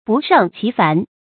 不胜其烦 bù shèng qí fán
不胜其烦发音
成语正音其，不能读作“qī”。